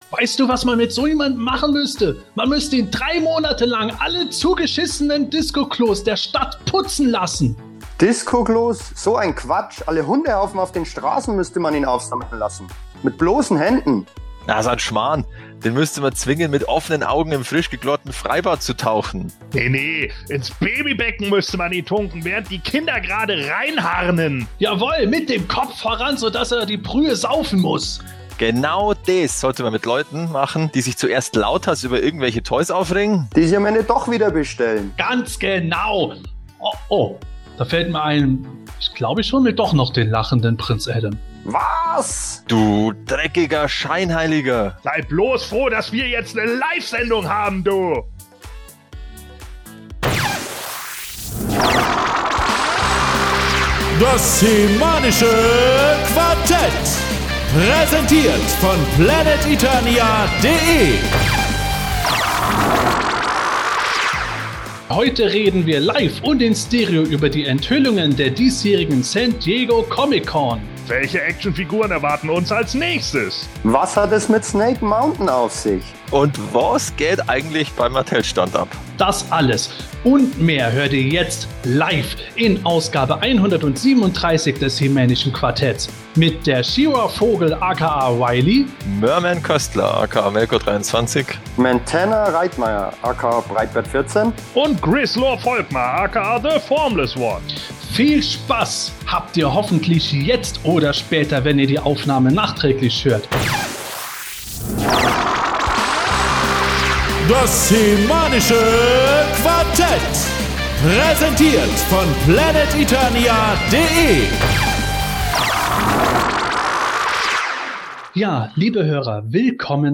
Das alles und mehr, jetzt noch einmal für alle in der geschnittenen Fassung in Ausgabe 137 des HE-MANischen Quartetts.